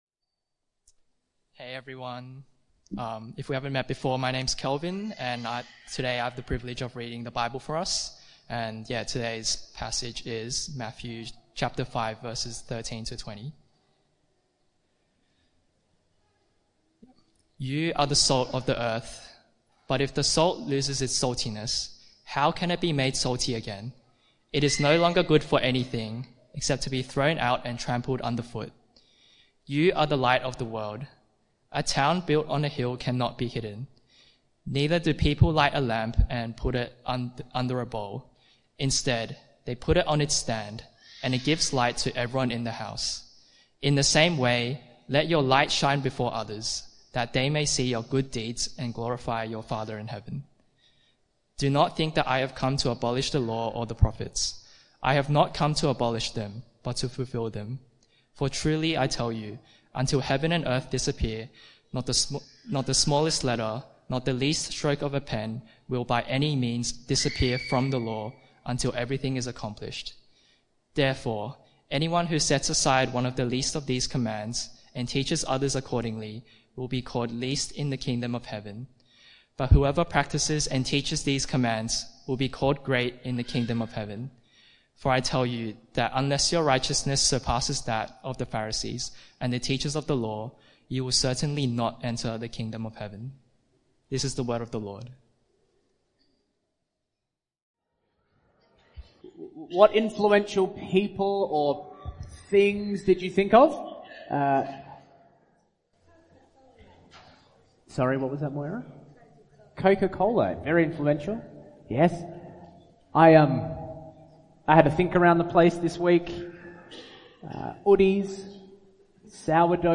This Bible talk explores the idea of Christian influence, using Jesus’ teaching on being “salt and light” (Matthew 5:13-16). It contrasts fleeting online trends and worldly influencers with the deep, lasting impact of Christians living out the gospel.